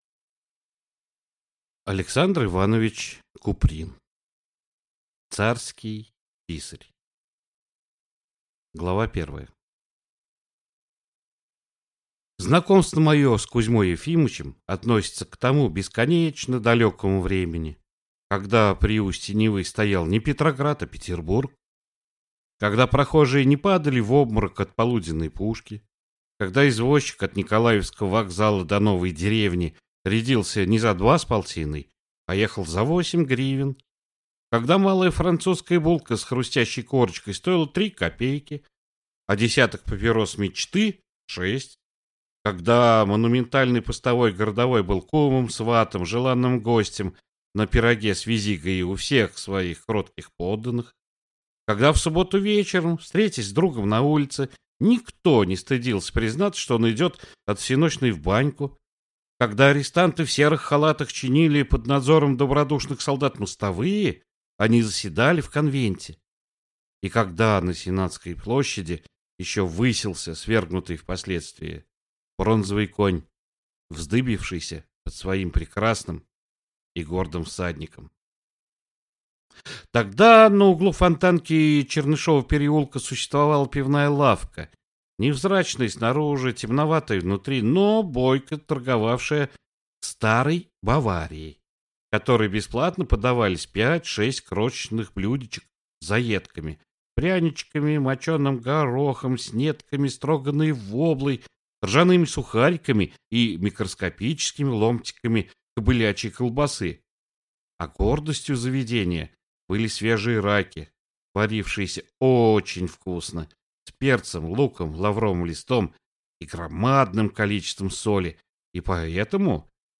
Аудиокнига Царский писарь | Библиотека аудиокниг